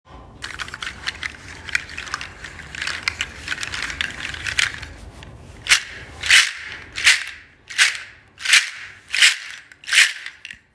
• large seed shaker originally from Togo
• loud hollow clacking sound
60 seed loop handle audio sample
Toga-loop-60-seed.m4a